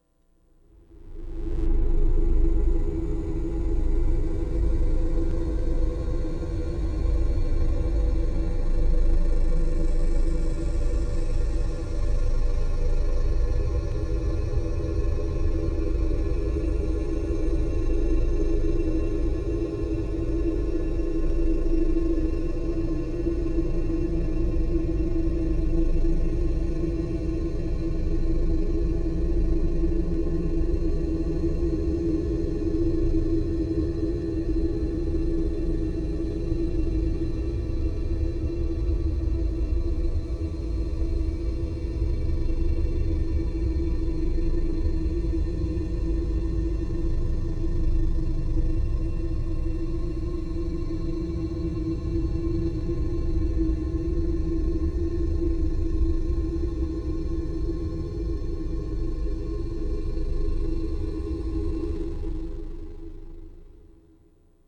Source: Resonated bowing on bridge (4:13-5:18)
Processing: granulated, stretch = 40:1, + KS=363 (E), F=1010